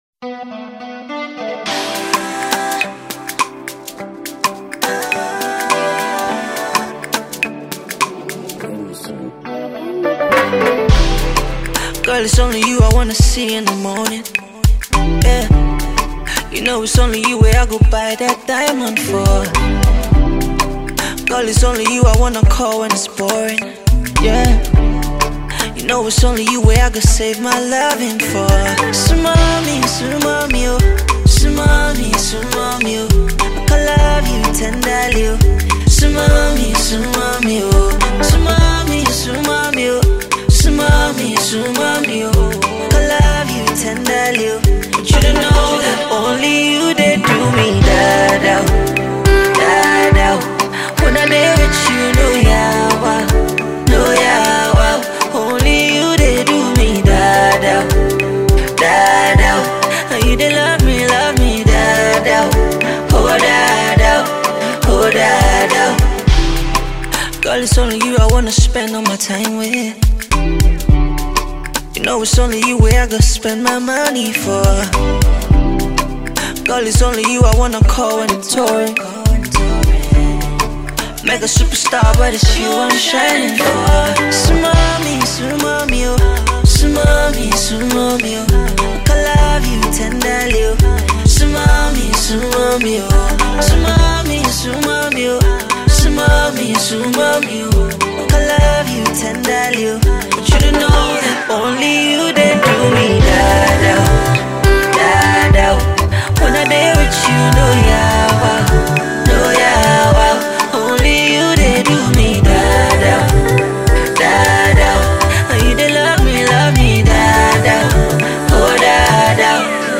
Silky Voice Nigerian songster